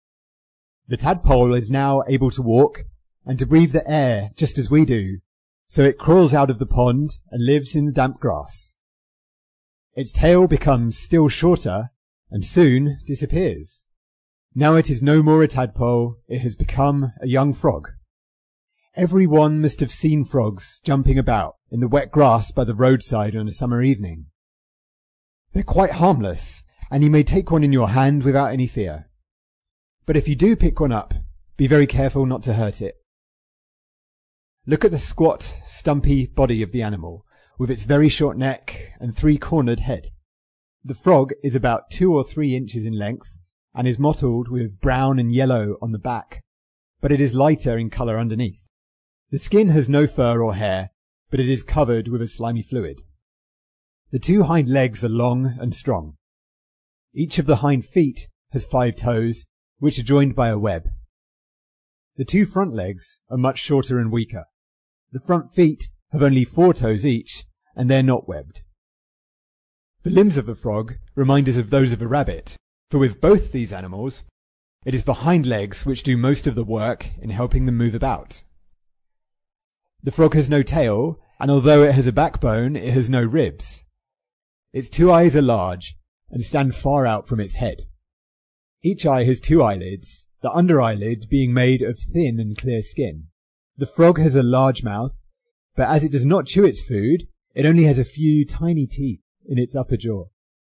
在线英语听力室提供配套英文朗读与双语字幕，帮助读者全面提升英语阅读水平。